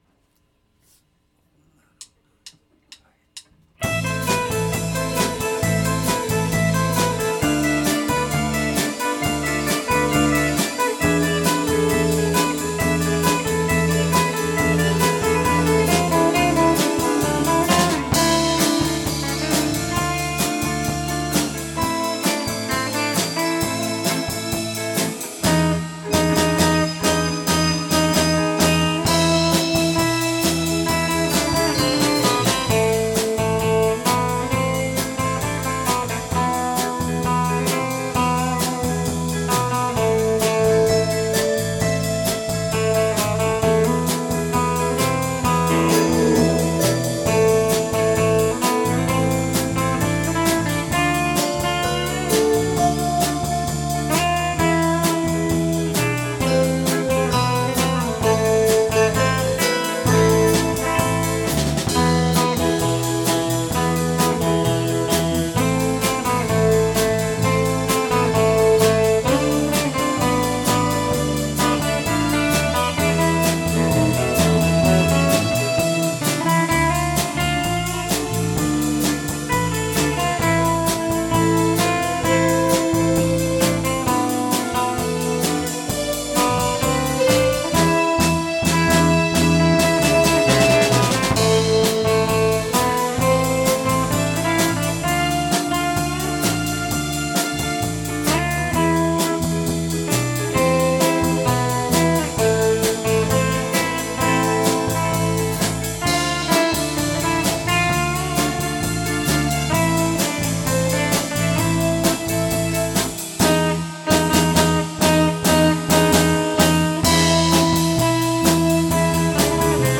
25th Live 2017 X’mas Live
１２月２３日（土）「ベンチャーズハウス六絃」にて「２０１７　Ｘ’mas　Ｌｉｖｅ」を開催しました、